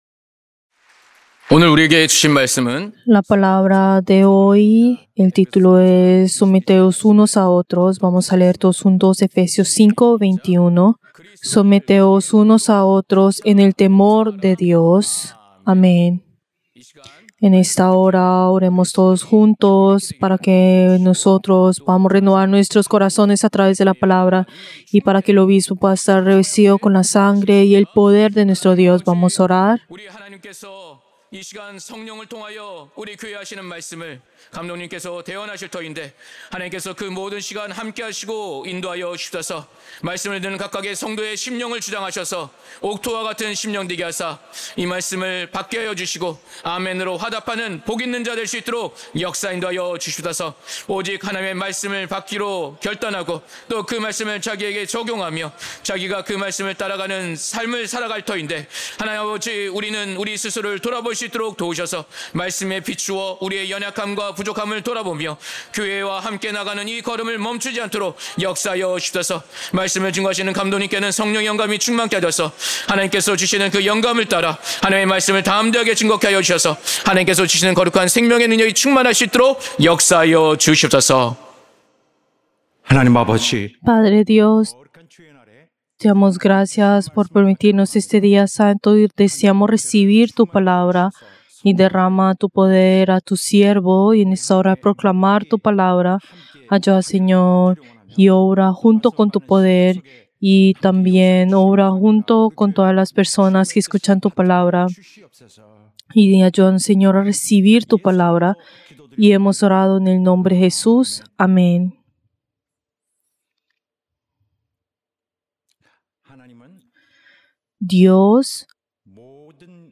Servicio del Día del Señor del 16 de febrero del 2025